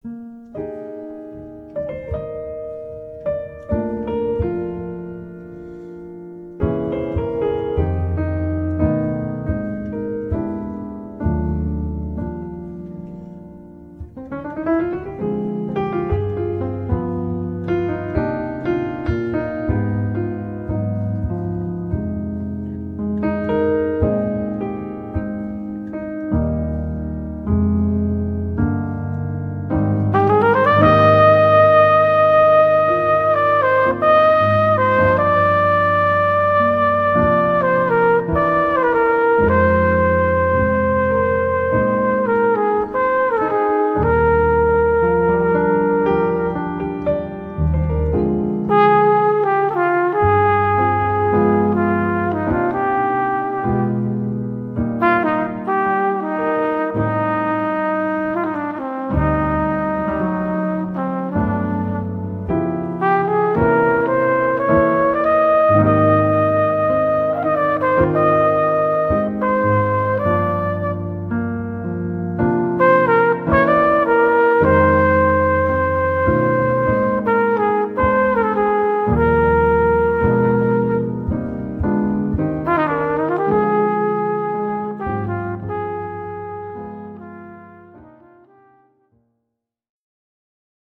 Jazz na CD
trumpet, flugelhorn
tenor saxophone
piano
bass
drums